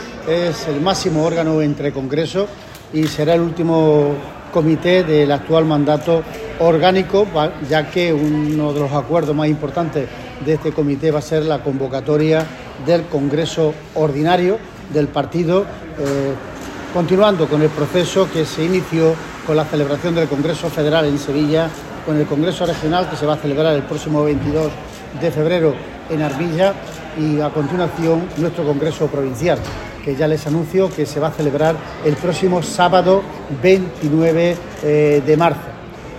Cortes de sonido